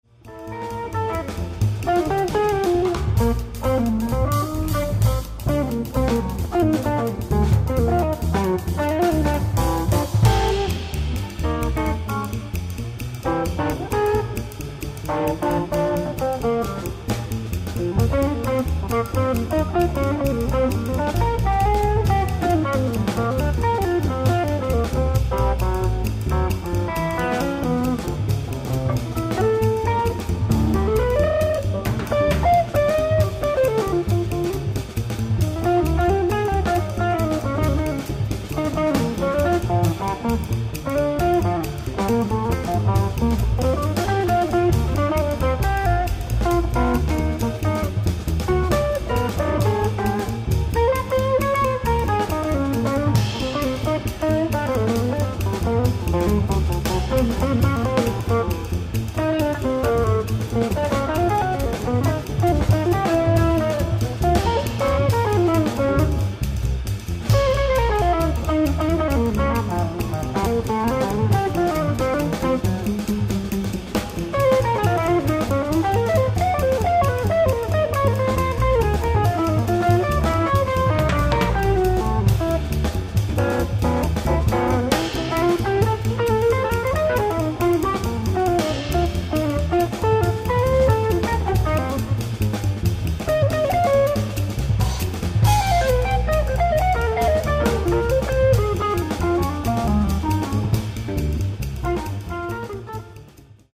ライブ・アット・ニューポートジャズ、ロードアイランド
※試聴用に実際より音質を落としています。